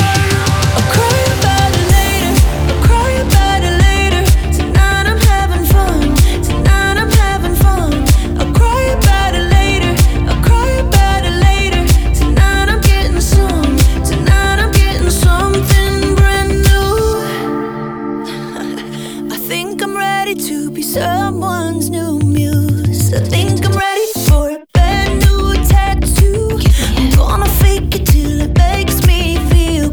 • Pop
pop song